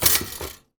R - Foley 250.wav